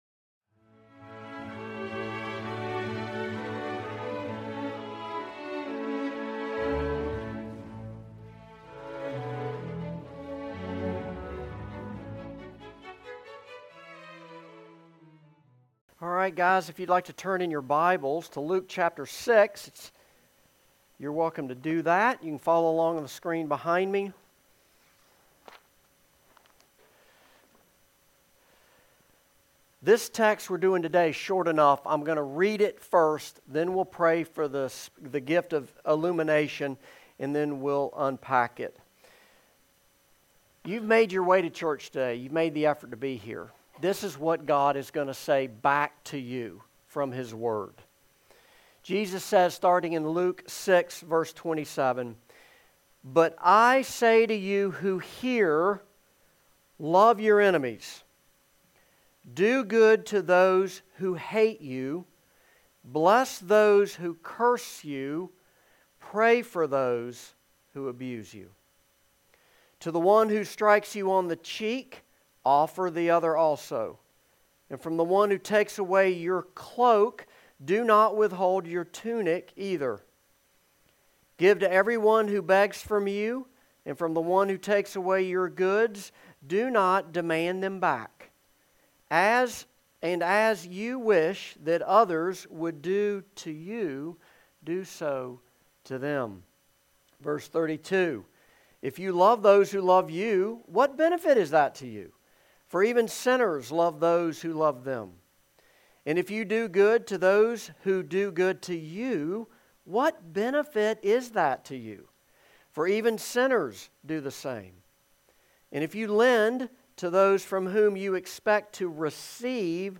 Service Type: Morning Service
Sermon-Intro_Joined-1.mp3